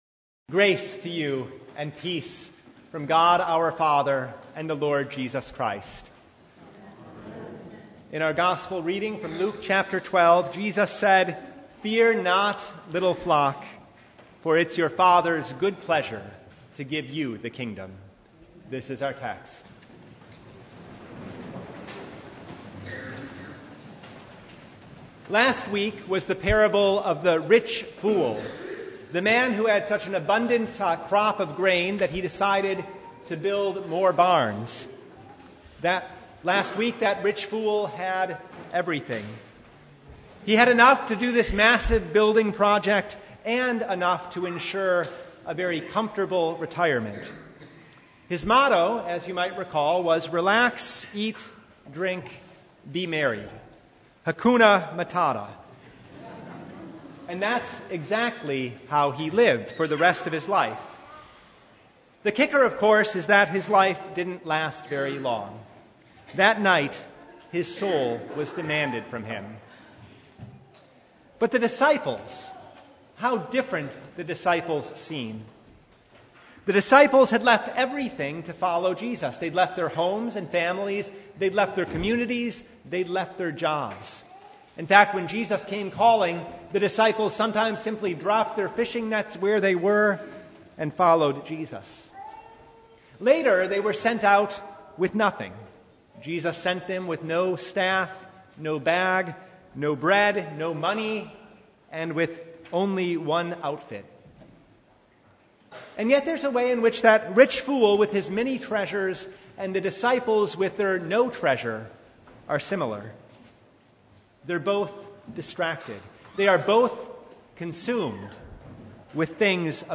Passage: Luke 12:22-34 Service Type: Sunday
Sermon Only